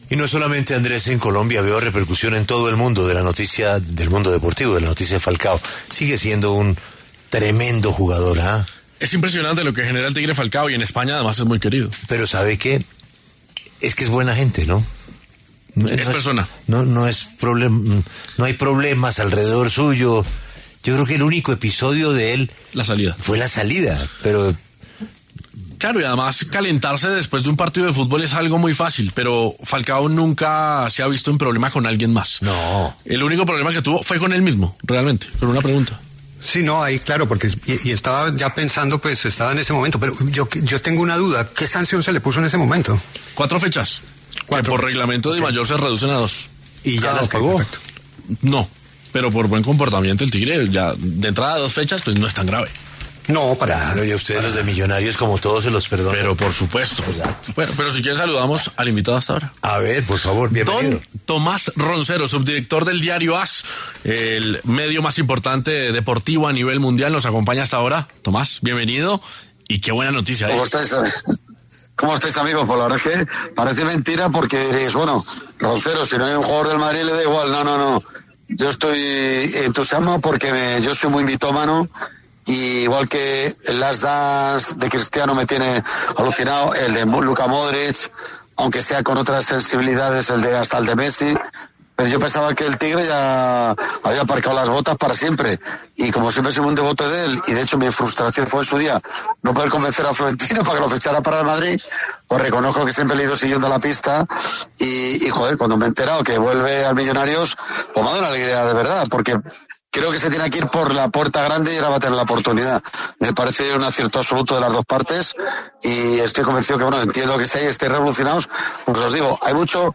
El reconocido periodista español Tomás Roncero, subdirector del Diario AS, conversó con La W acerca del regreso de Radamel Falcao García a Millonarios para afrontar una nueva temporada en la Liga BetPlay.